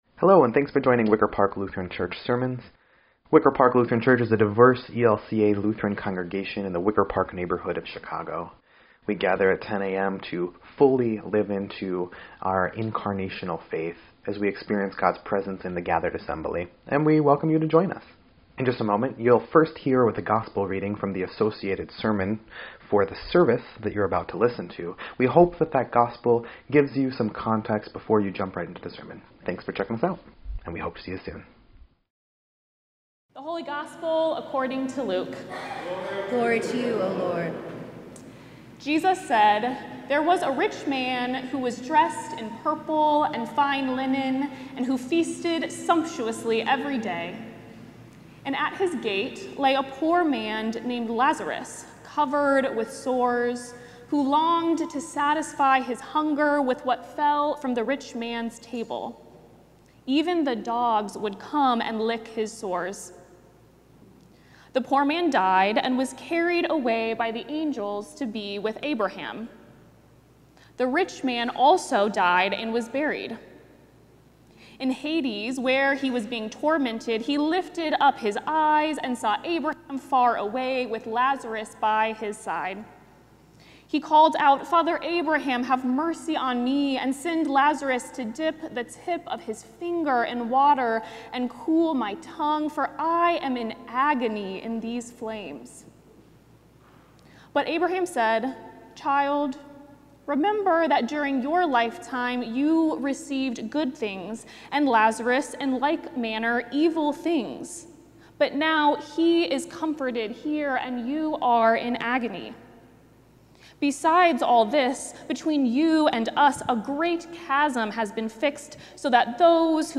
9.28.25-Sermon_EDIT.mp3